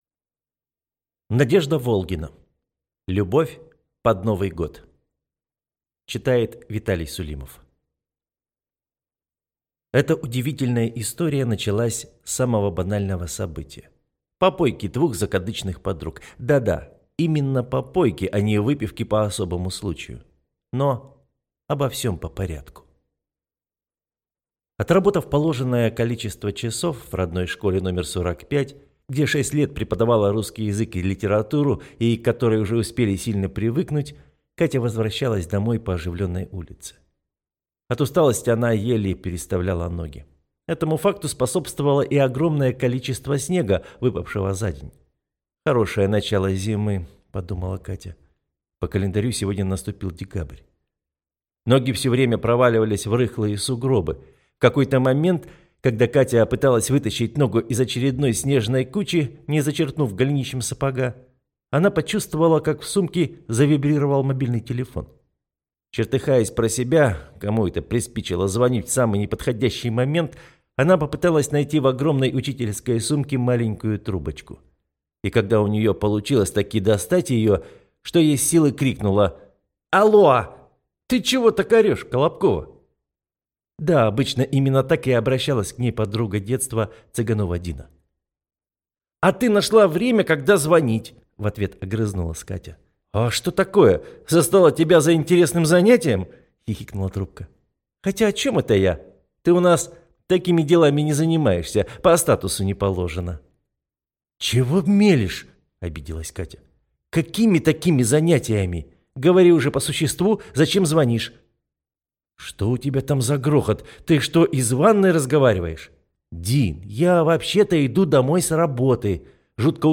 Аудиокнига Любовь под Новый год | Библиотека аудиокниг